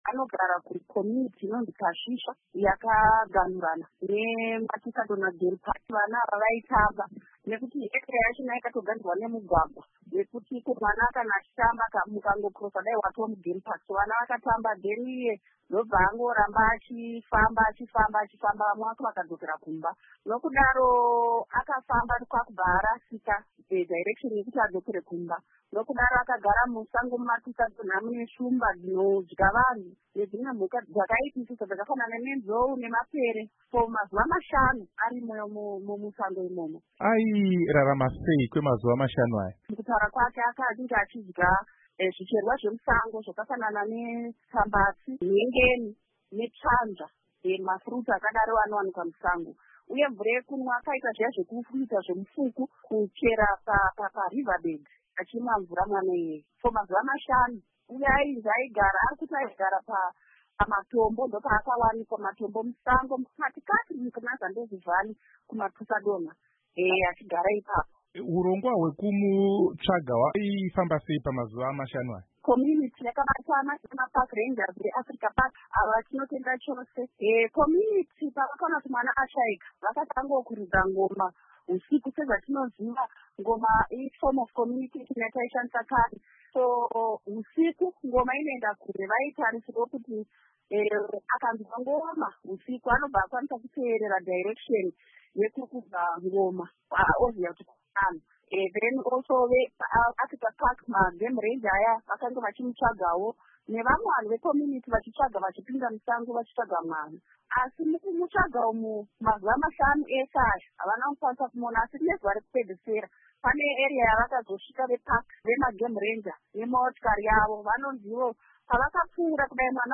Mumiriri wenzvimbo iyi mudare reparamende Muzvare Mutsa Murombedzi vebato reCitizens Coalition for Change vaudza Studio 7 kuti mwana uyu akapona nekudya michero yemusango uye kuchera mufuku kuti awane mvura yekunwa.
Hurukuro naMuzvare Mutsa Murombedzi